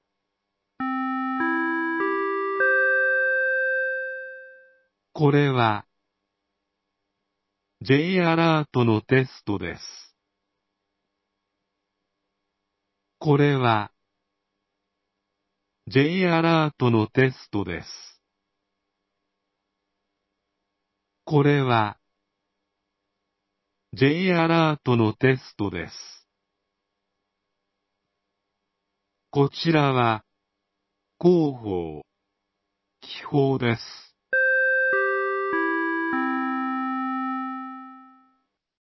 防災無線放送がありました